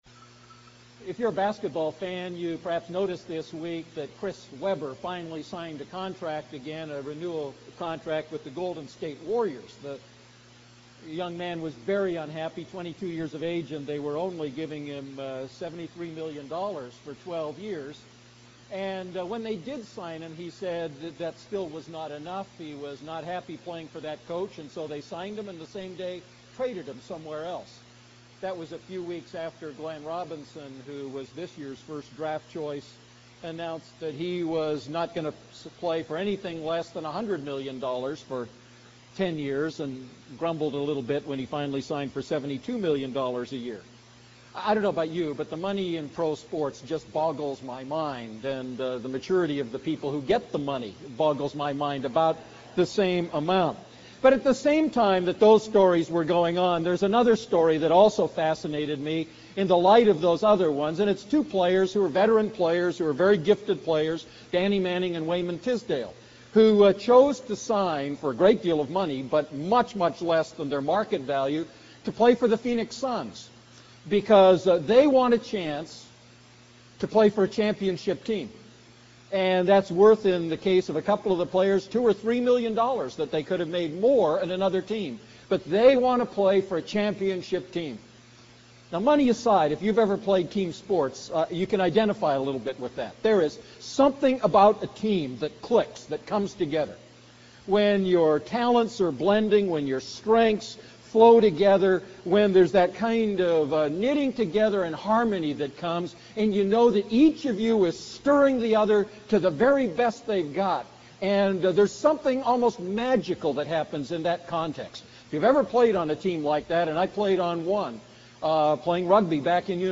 A message from the series "1 Thessalonians."